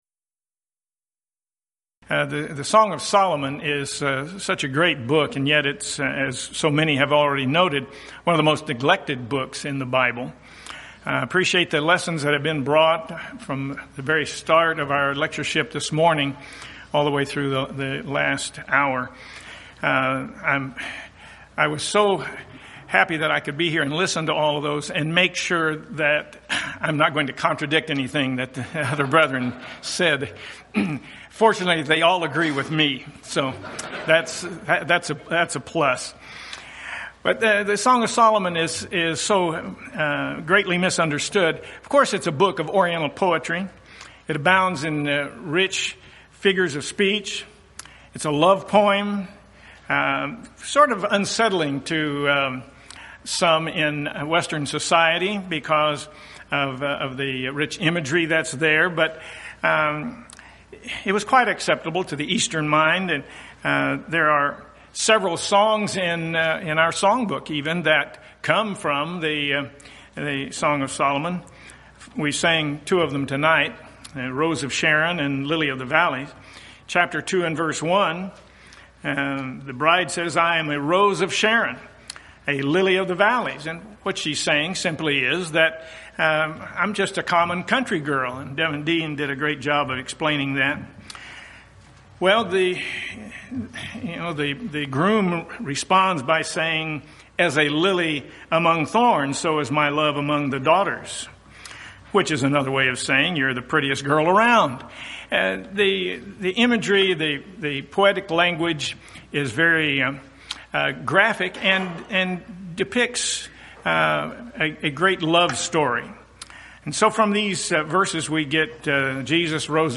Event: 13th Annual Schertz Lectures Theme/Title: Studies in Proverbs, Ecclesiastes, & Song of Solomon
lecture